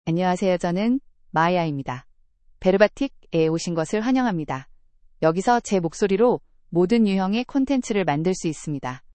Maya — Female Korean (Korea) AI Voice | TTS, Voice Cloning & Video | Verbatik AI
Maya is a female AI voice for Korean (Korea).
Voice sample
Female
Maya delivers clear pronunciation with authentic Korea Korean intonation, making your content sound professionally produced.